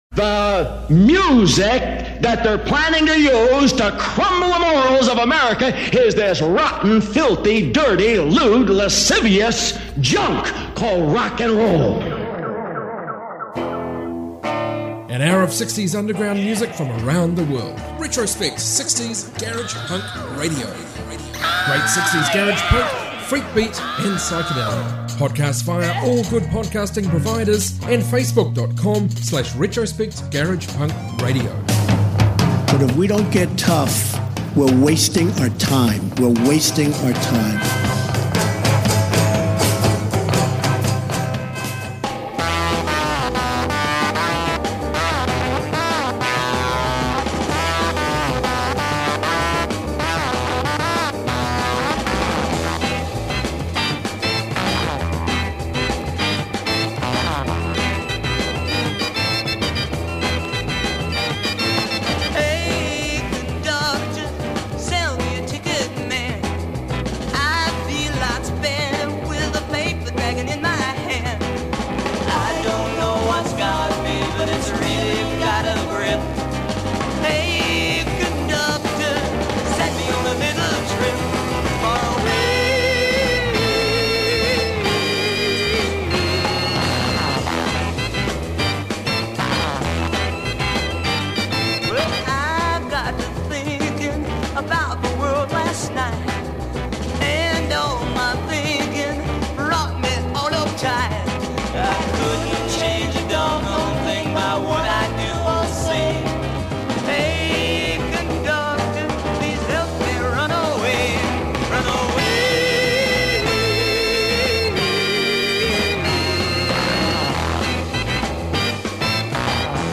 60s garage punk